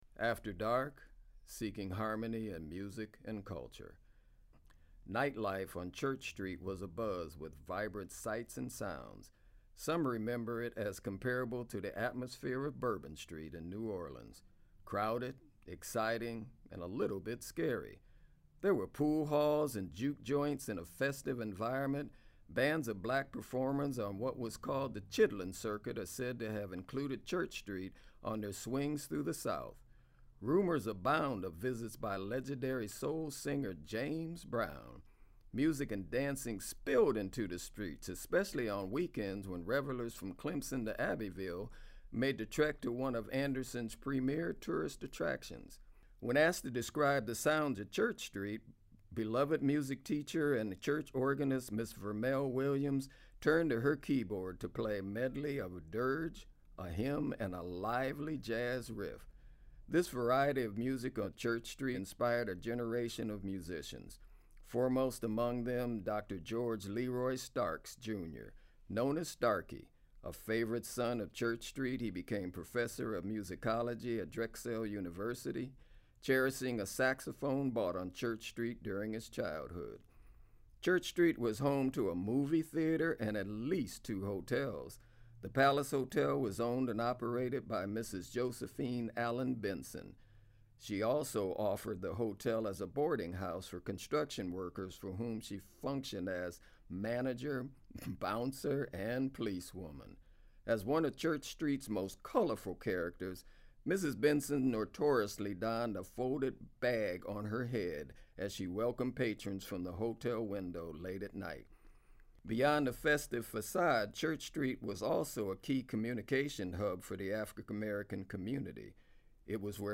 turned to her keyboard to play a medley of a dirge, a hymn and lively jazz rift